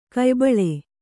♪ kaybaḷe